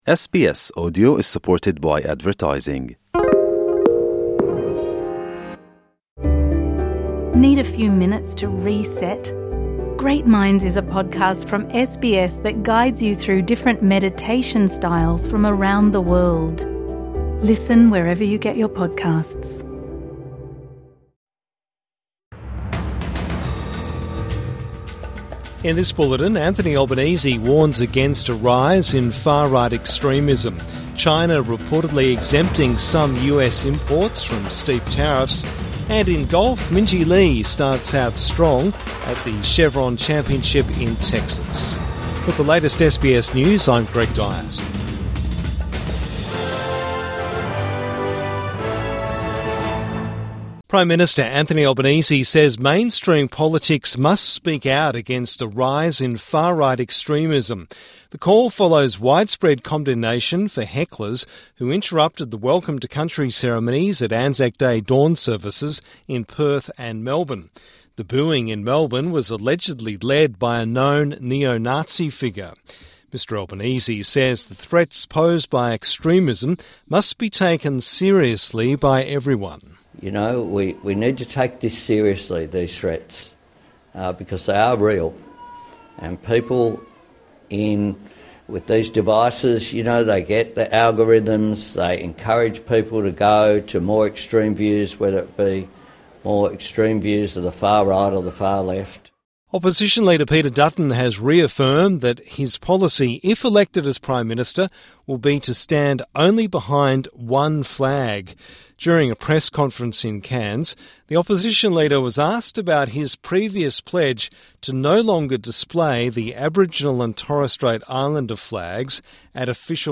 Evening News Bulletin 26 April 2025